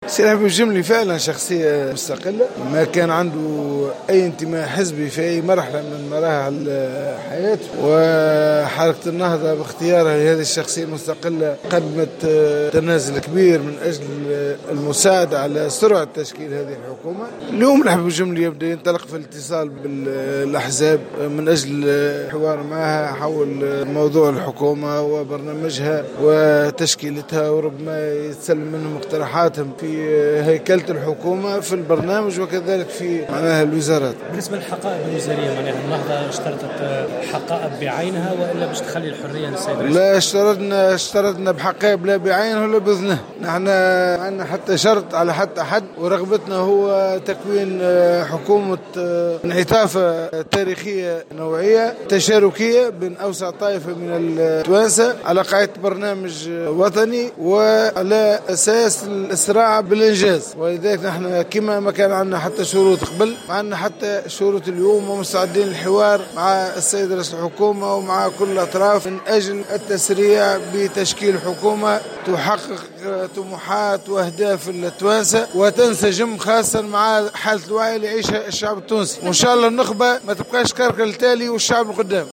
قال القيادي في حركة النهضة نور الدين البحيري في تصريح لمراسل الجوهرة اف ام اليوم...